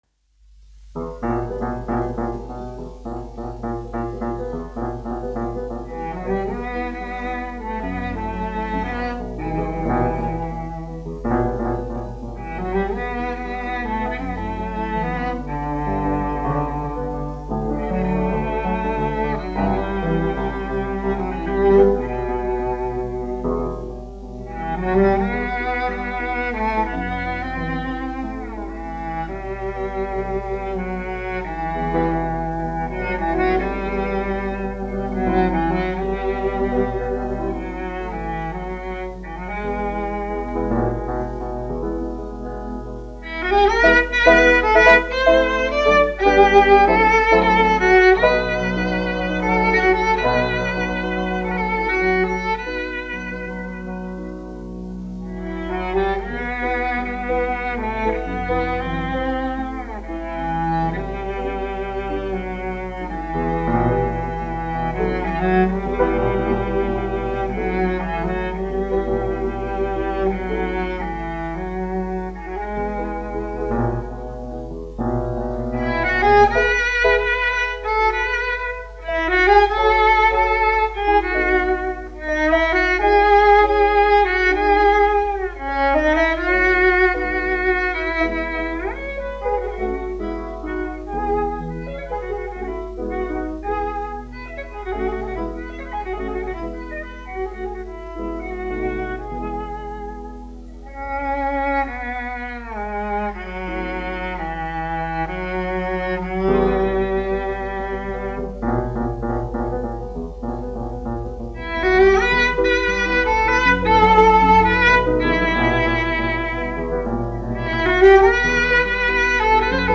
1 skpl. : analogs, 78 apgr/min, mono ; 25 cm
Čella un klavieru mūzika, aranžējumi
Latvijas vēsturiskie šellaka skaņuplašu ieraksti (Kolekcija)